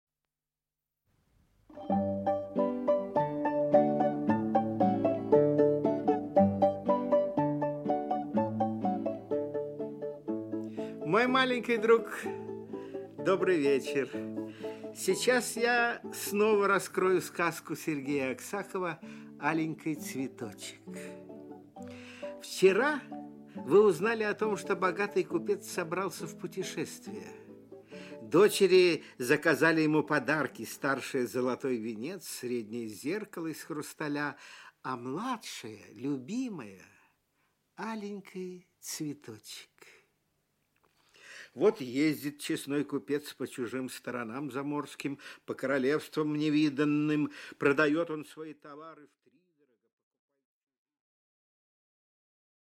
Аудиокнига Аленький цветочек. Часть 2 | Библиотека аудиокниг
Часть 2 Автор Сергей Аксаков Читает аудиокнигу Николай Литвинов.